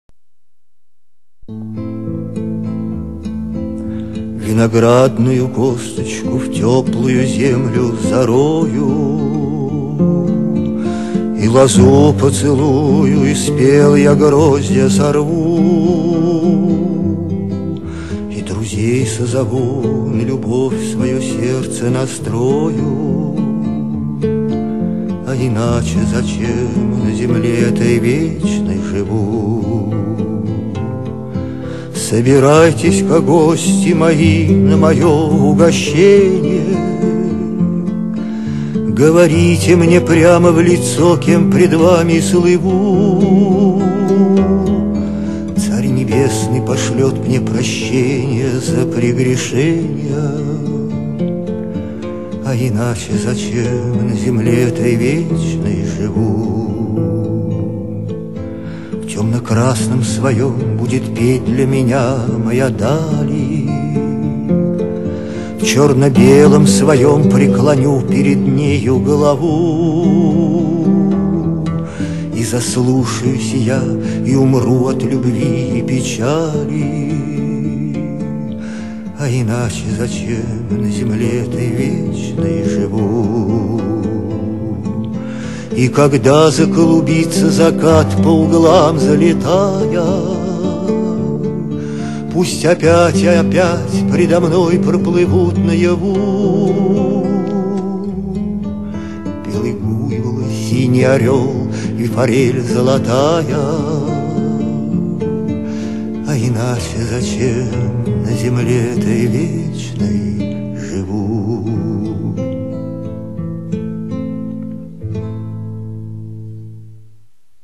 歌です